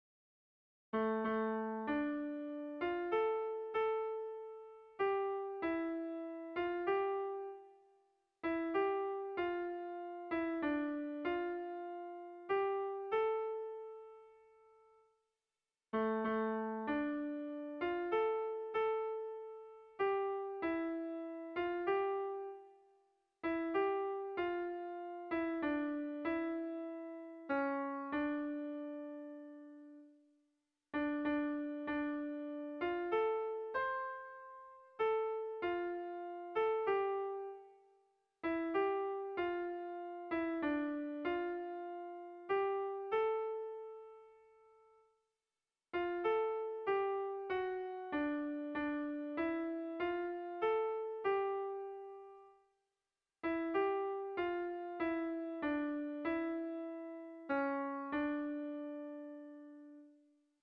Bertso melodies - View details   To know more about this section
Kontakizunezkoa
Zortziko handia (hg) / Lau puntuko handia (ip)
A1A2BA3